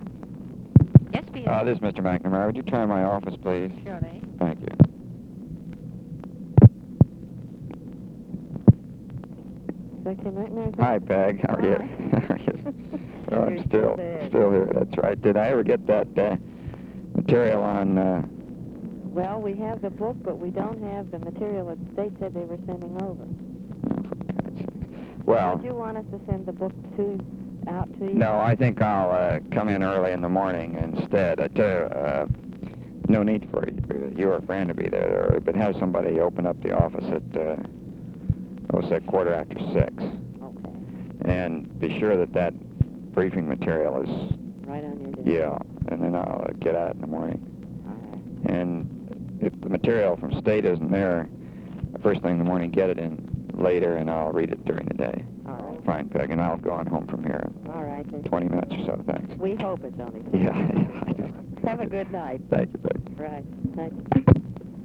Conversation with ROBERT MCNAMARA
Secret White House Tapes